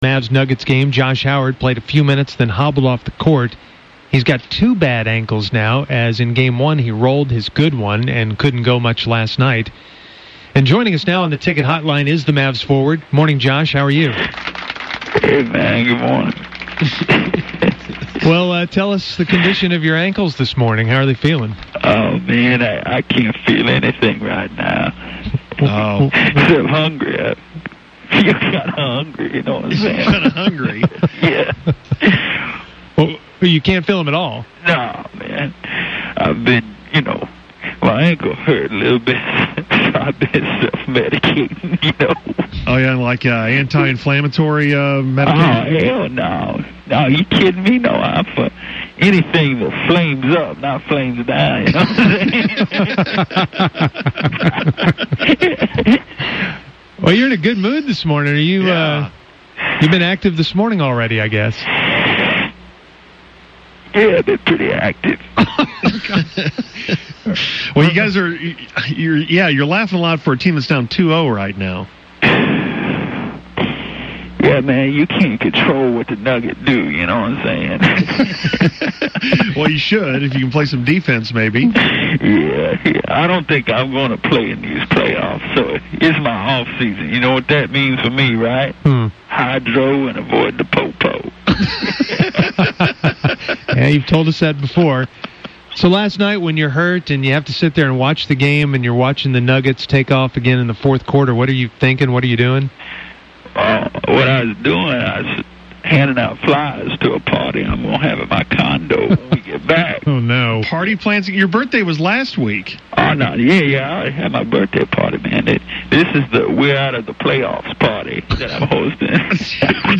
Seemed to be taking a lot of deep breaths and exhaling quite a bit.